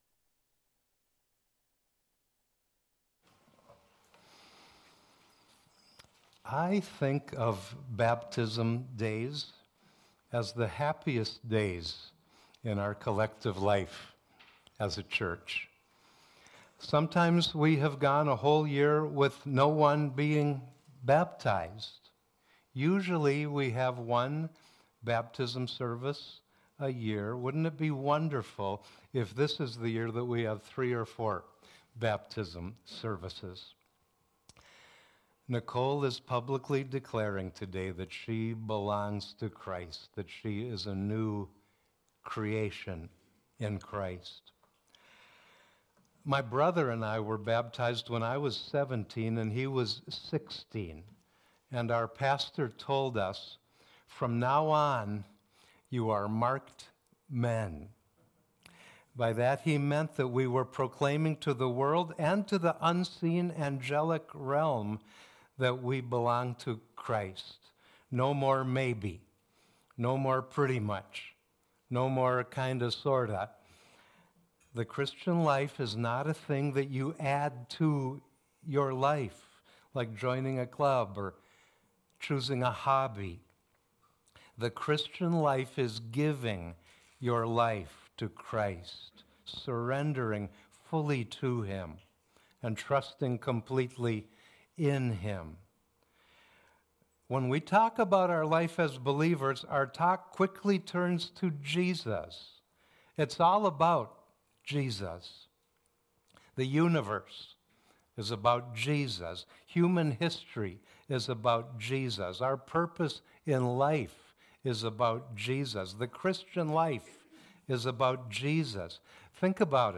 Series: Miscellaneous Sermons & Testimonies